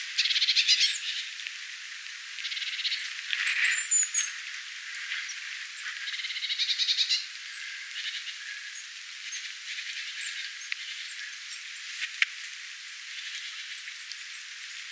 sinitiainen, pieni tiaisparvi
sinitiainen_pieni_tiaisparvi_valkoinen_janis_oli_kuin_tiaisten_kanssa.mp3